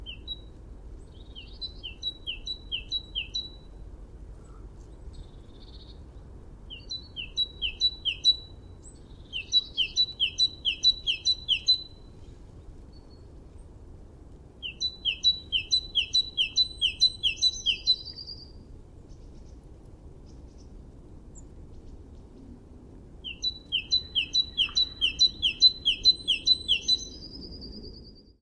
Bereits im Februar hört man die typischen Gesangsstrophen, die den Frühling einläuten.
Stimme: Das Stimmenrepertoire der Kohlmeise ist sehr umfangreich und variabel. Der arttypische Gesang ist kräftiger als bei den anderen Meisen. Übrigens singen auch die Weibchen. Häufige Rufe: "ze wide wit", oder "zi-dähr", "zizibäh zizibäh". Der Gesang sind zumeist rau pfeifende Laute.
kohlmeise29.mp3